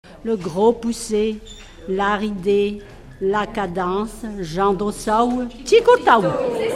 formulette enfantine : jeu des doigts
Pièce musicale inédite